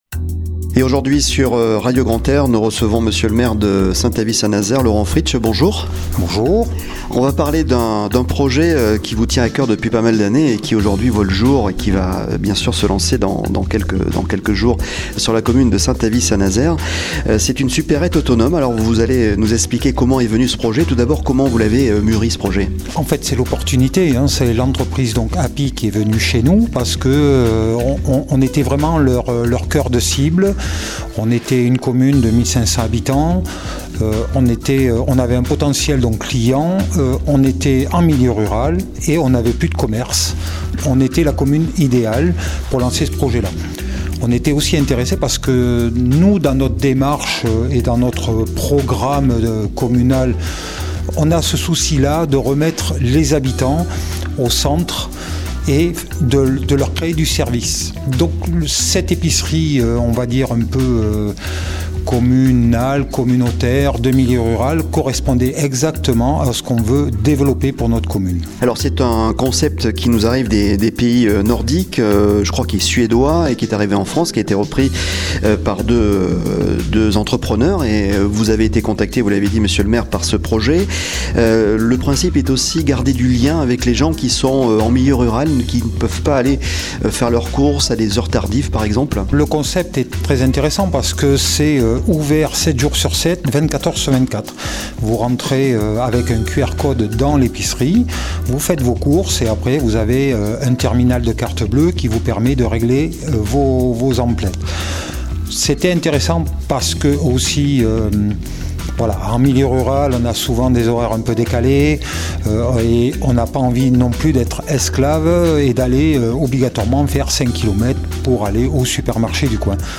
Interview Laurent FRITSCH pour l'ouverture d'une superette autonome API
Laurent Fritsch le maire de SAINT AVIT ST NAZAIRE revient sur le projet au micro de Radio Grand "R" !